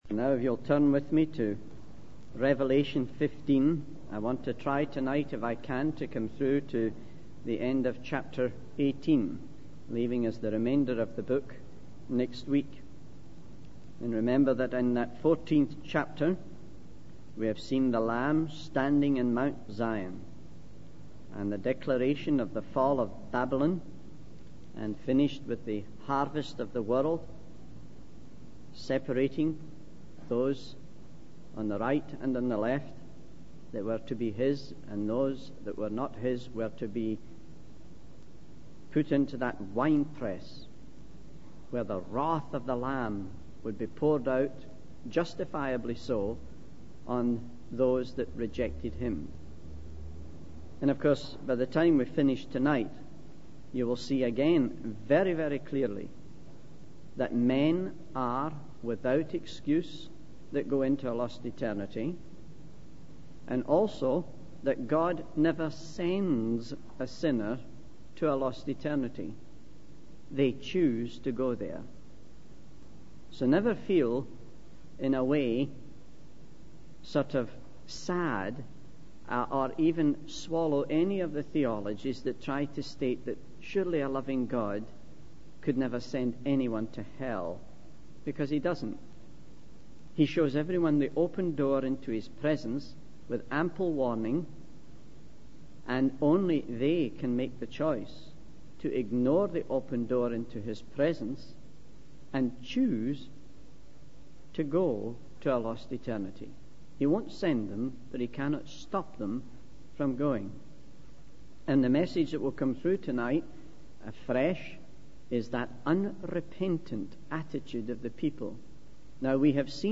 The sermon culminates in the depiction of the fall of Babylon and the ultimate judgment that awaits those who continue in their rebellion against God.